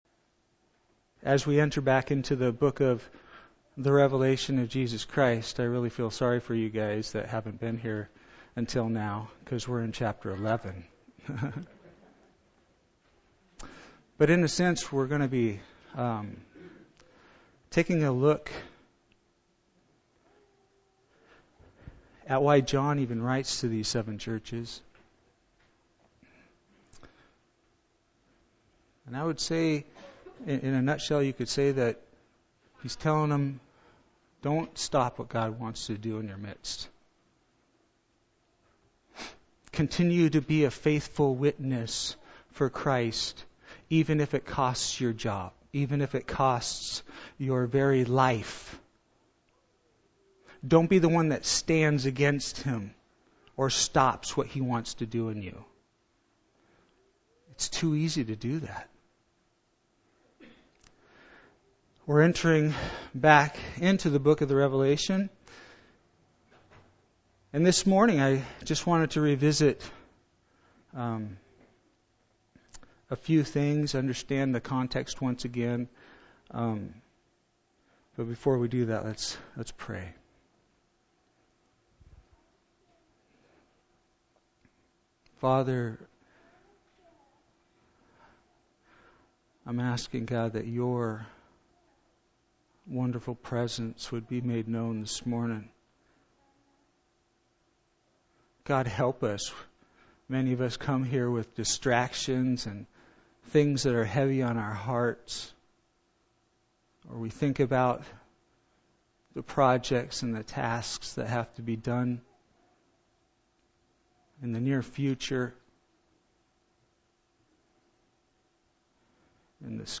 This is one of those sermons that no one really wants to hear, but everyone needs to hear.